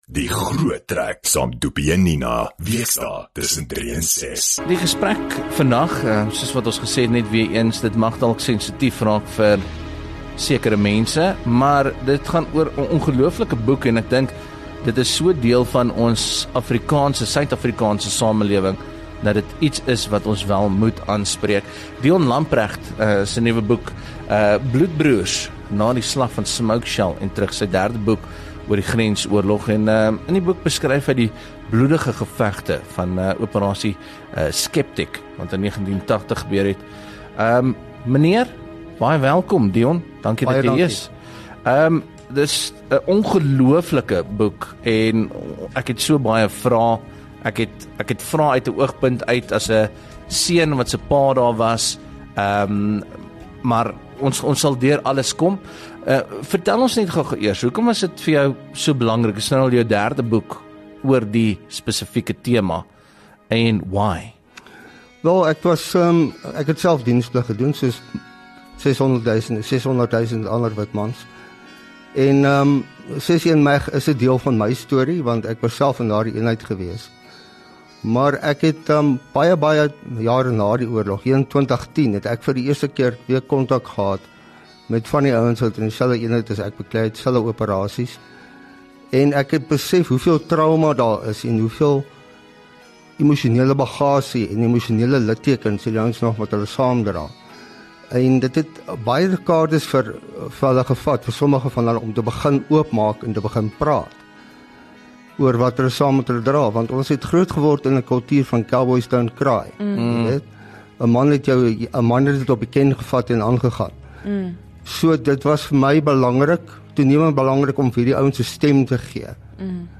hy kom gesels vandag met ons hieroor. Onder ander die gebeure van 10 Junie 1980 gedurende ’n eindelose dag van bloedige gevegte onder die Angolese son toe 13 lede van die Suid-Afrikaanse Weermag dood en etlike beseer was na 61 Gemeganiseerde Bataljon Groep ’n kompleks van Swapo-basisse in die suide van Angola aangeval het. Bloedbroers vertel die storie van die oorlewendes en hul ervarings tydens die geveg.